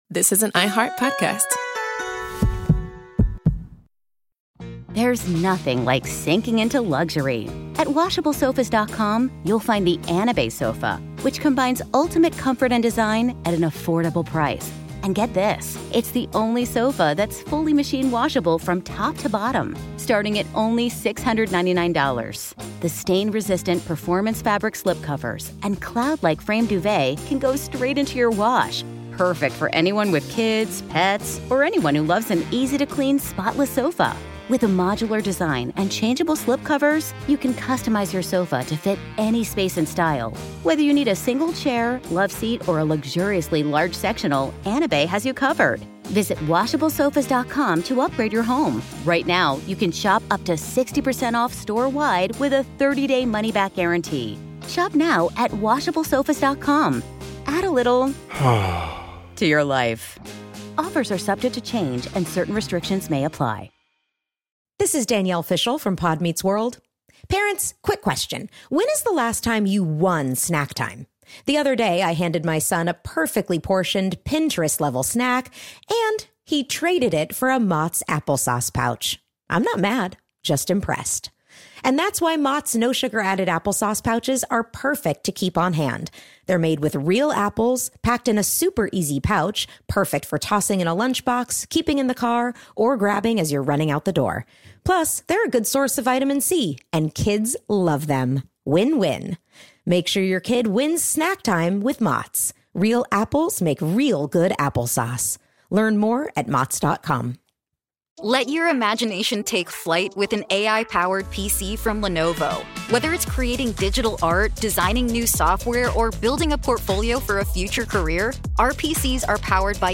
His Pyramid of Success reshaped how we think about coaching, competition, and character. Told through the voices of those who knew him best, this story explores how a humble Indiana native became one of the most respected figures in sports history.